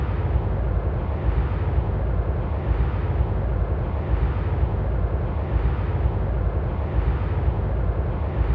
Propagation effects in the synthesis of wind turbine aerodynamic noise | Acta Acustica
Test cases C: xR = 500 m, medium turbulence and grass ground in summer.